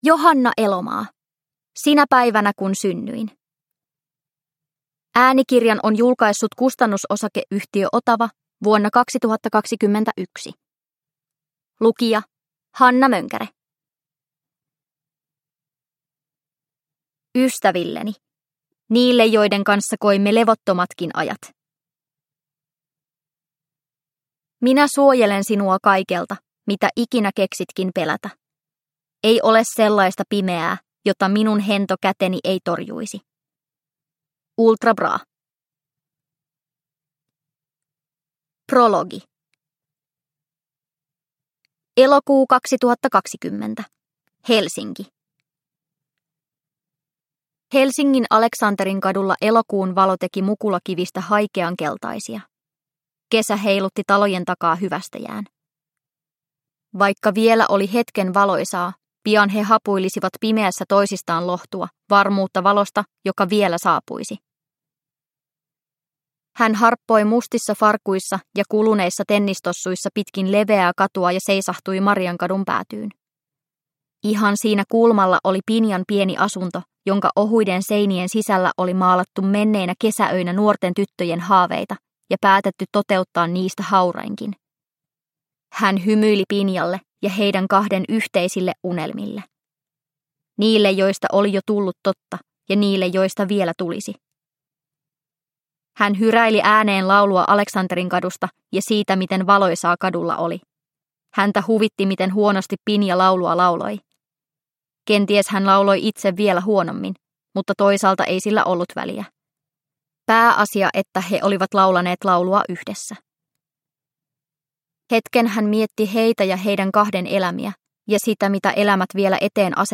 Sinä päivänä kun synnyin – Ljudbok – Laddas ner